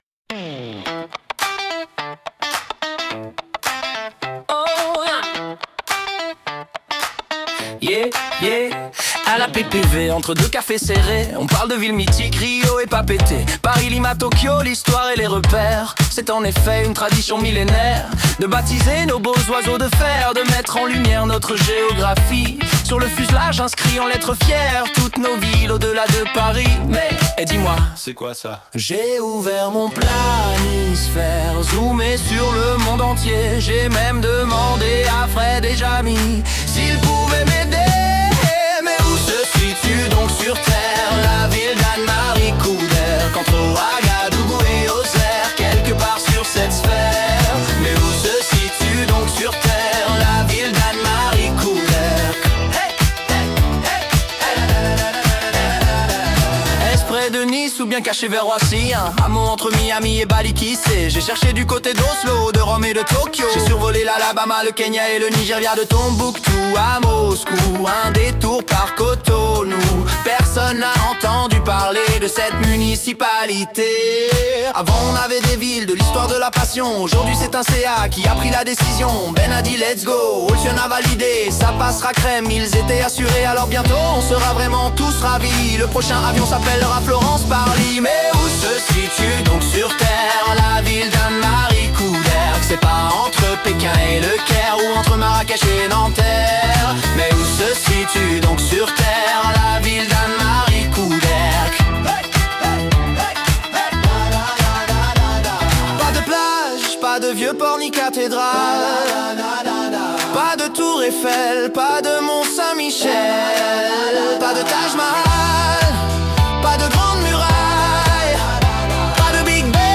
Une chanson qui nous a bien fait marrer !